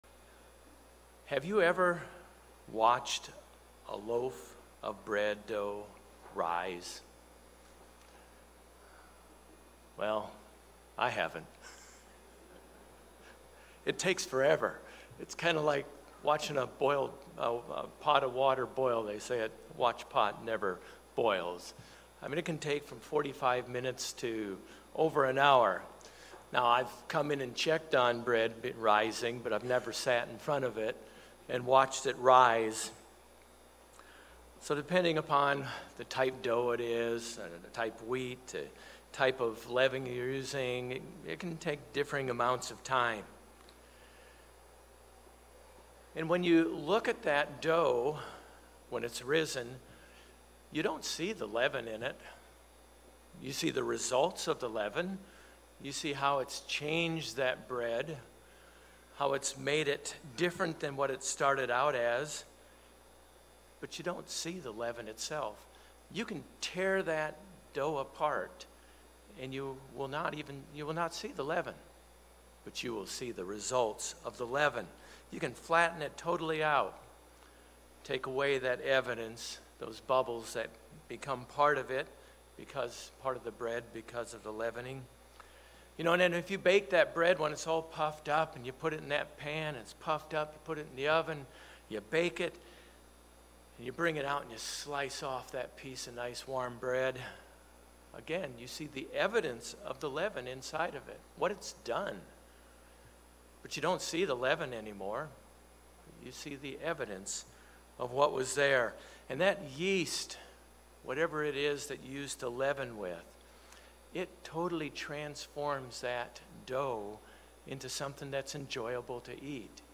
This sermon reviews six signs that You Might Be A Hypocrite.